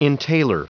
Prononciation du mot : entailer